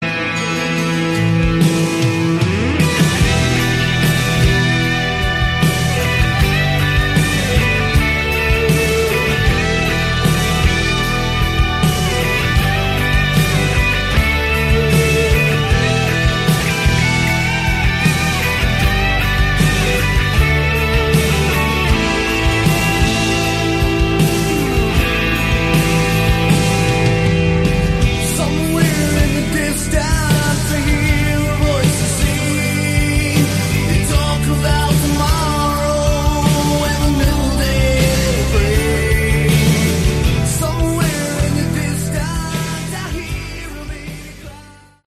Category: Hard Rock
Vocals, Guitars, Keyboards, Piano